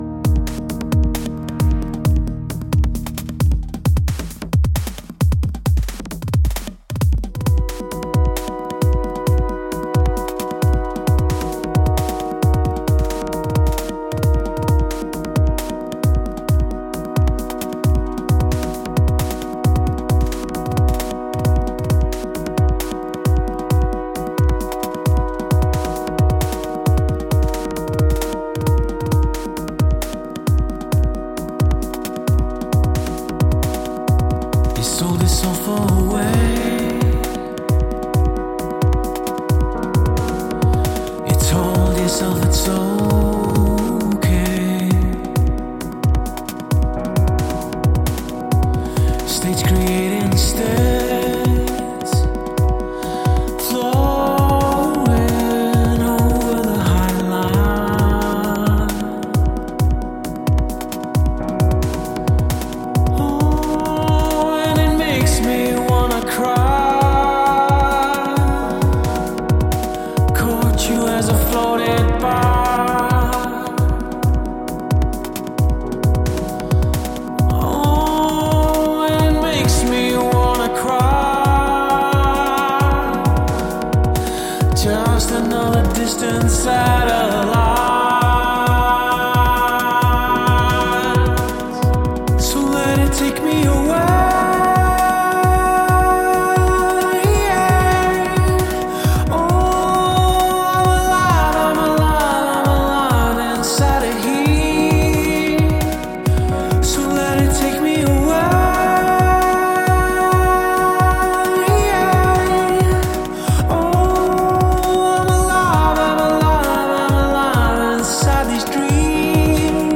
پراگسیو راک Progsive rock